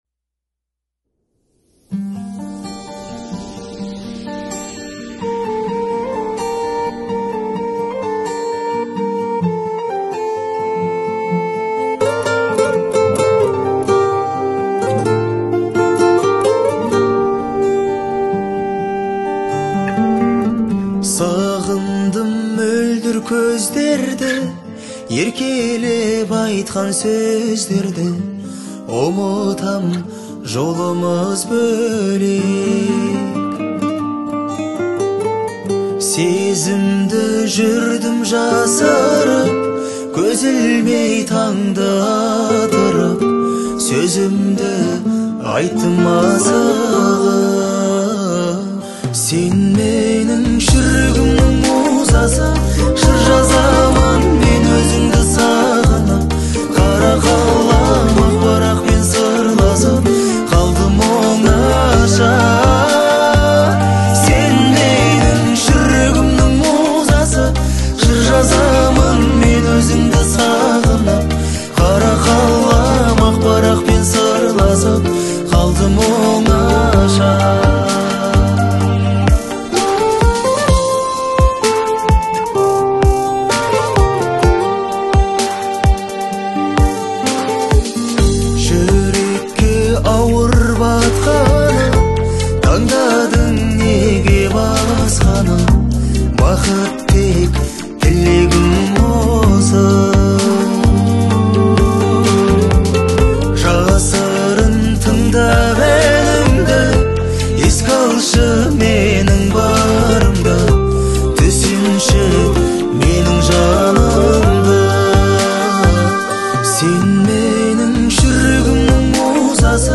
отличается мелодичностью и гармоничными аранжировками